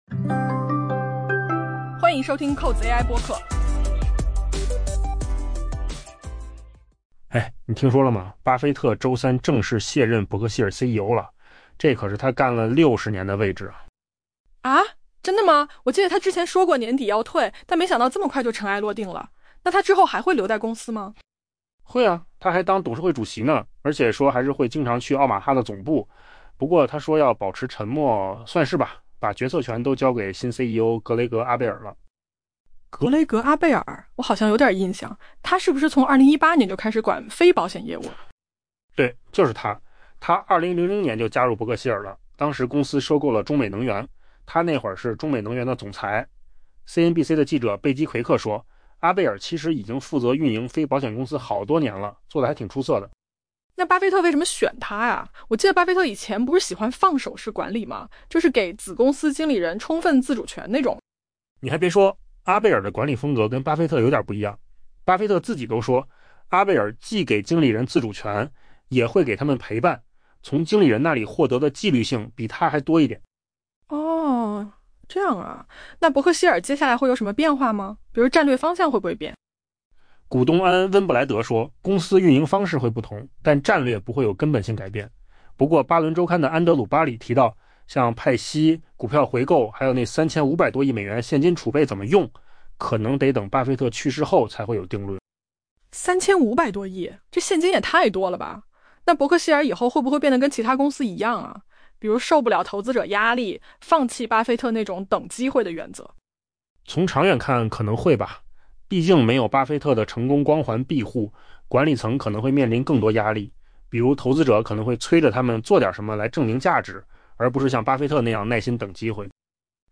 AI 播客：换个方式听新闻 下载 mp3 音频由扣子空间生成 在通过那笔被他称为人生中 「最愚蠢」 的投资为自己买下这份工作 60 年后，周三成为沃伦·巴菲特 （Warren Buffett） 担任伯克希尔·哈撒韦 （BRK） 首席执行官的最后一天。